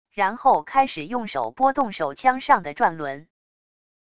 然后开始用手拨动手枪上的转轮wav音频